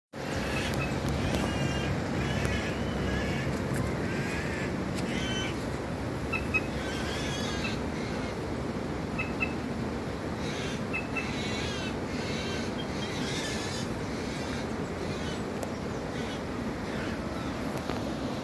Plush-crested Jay (Cyanocorax chrysops)
Sex: Both
Detailed location: Termas de Reyes
Condition: Wild
Certainty: Recorded vocal